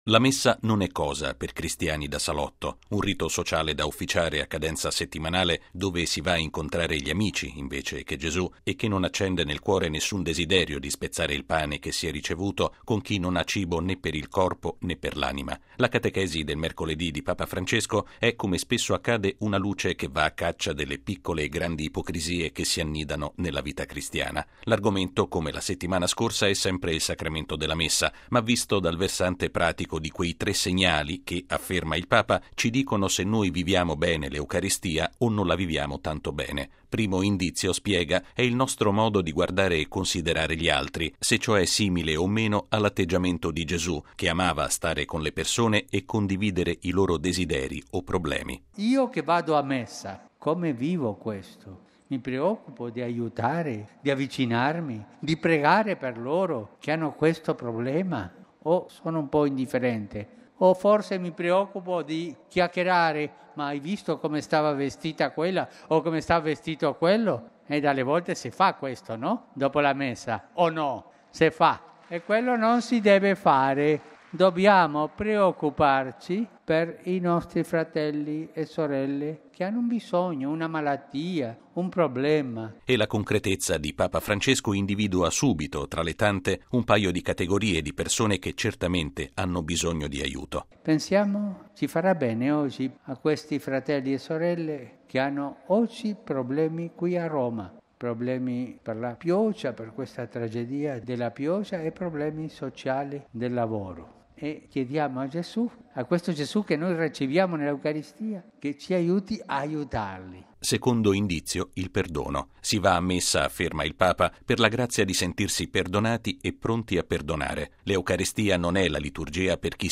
◊   Un cristiano che viva realmente la Messa non può non preoccuparsi di chi ha bisogno di aiuto. Lo ha affermato Papa Francesco all’udienza generale di questa mattina in Piazza San Pietro, davanti a circa 30 mila persone.